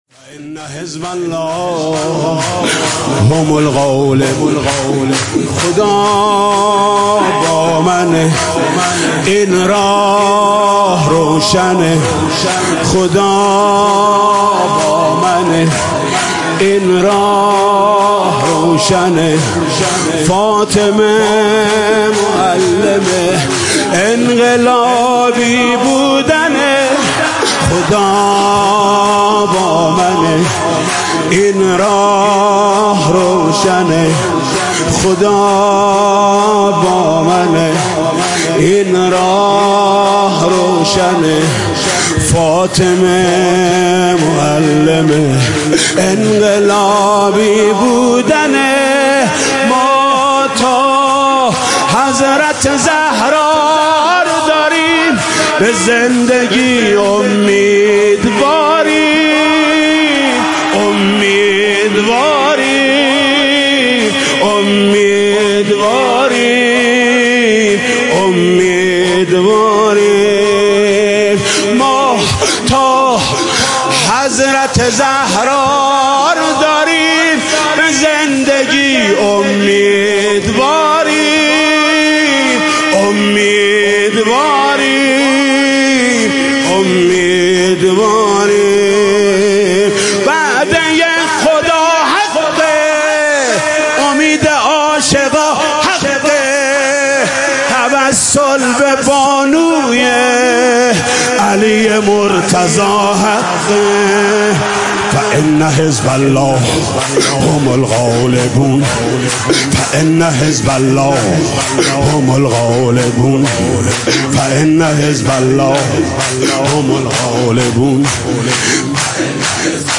شب سوم ایام فاطمیه
شور